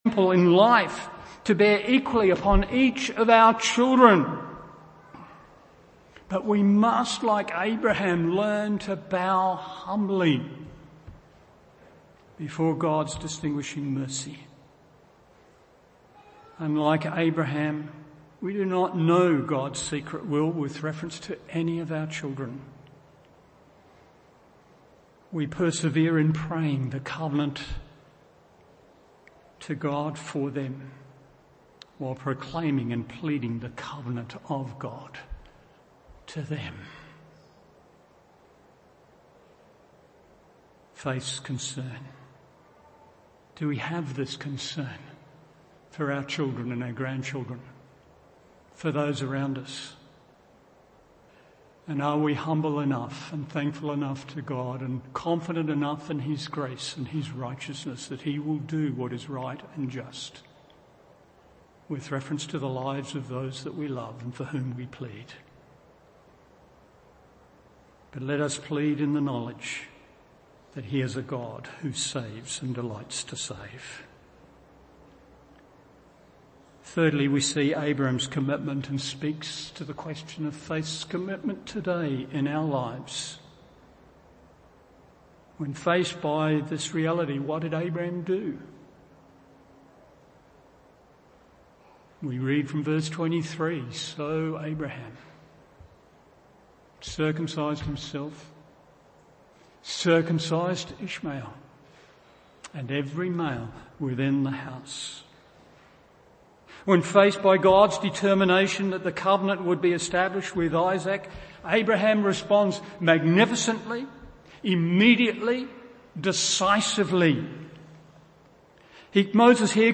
Apologizes but due to an issue with the recorder only the last few minuets of the sermon were recorded Gen 17:15-27
Morning Service